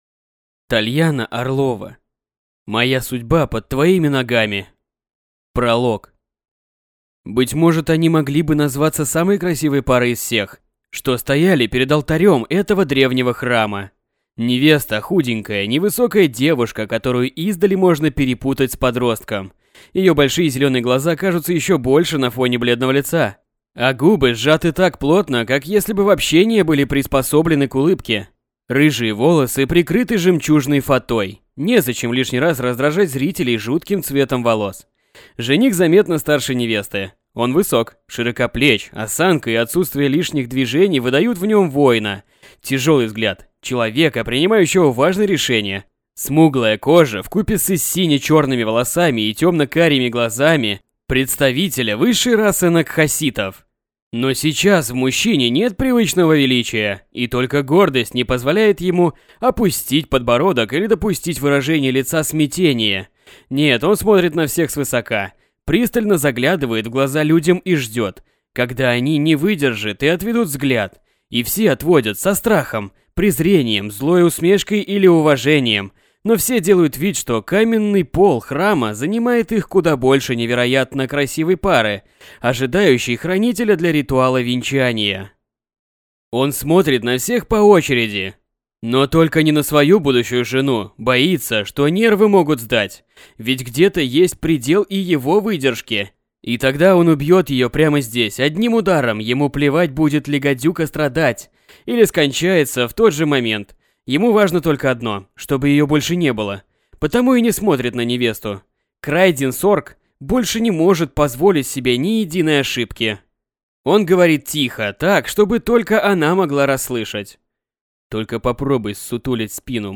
Аудиокнига Моя судьба под твоими ногами | Библиотека аудиокниг